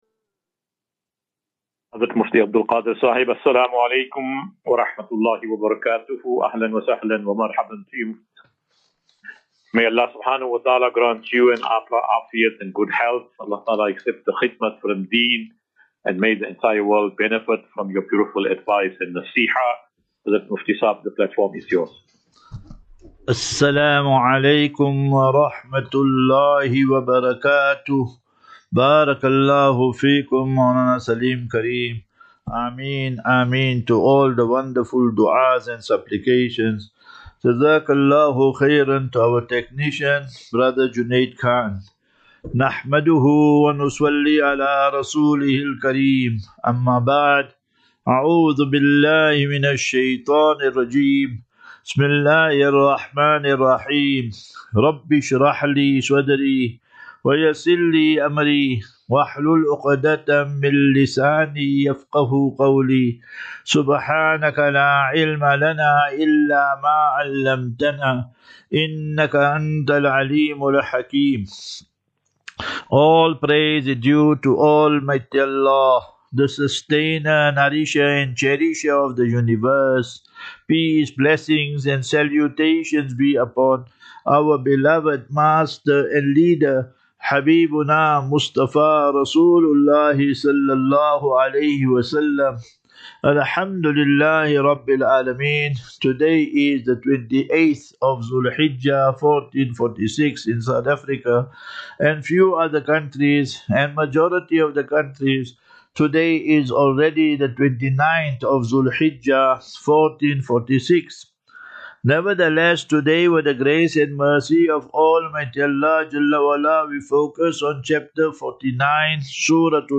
25 Jun 25 June 2025. Assafinatu - Illal - Jannah. QnA